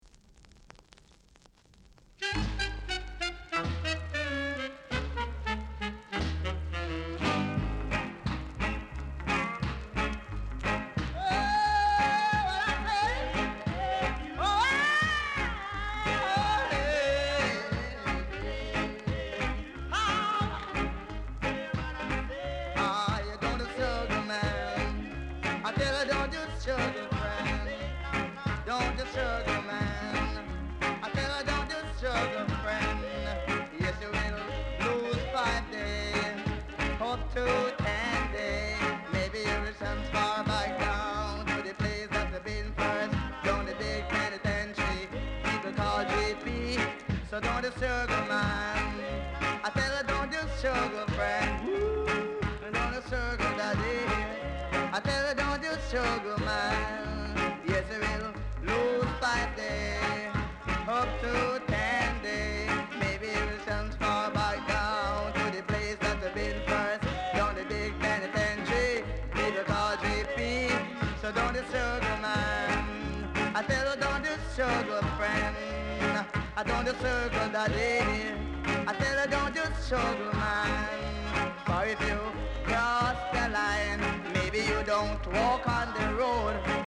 Vocal Group
Rare! great rock steady inst & vocal!